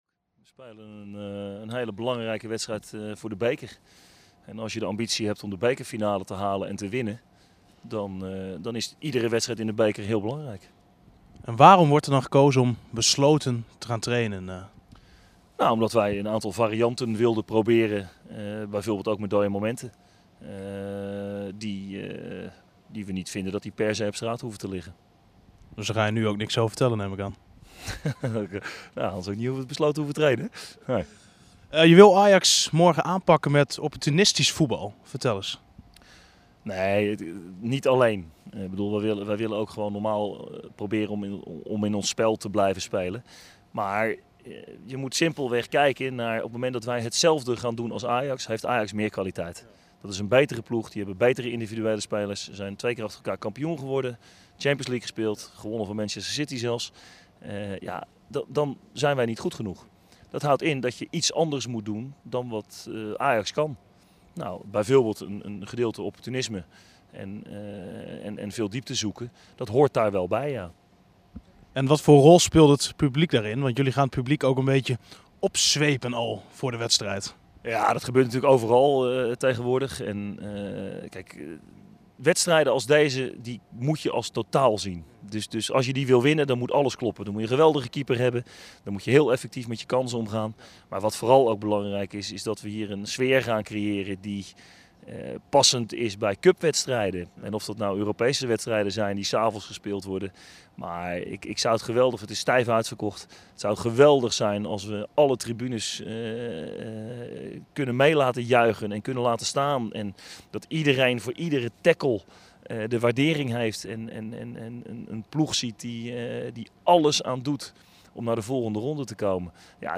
Verslaggever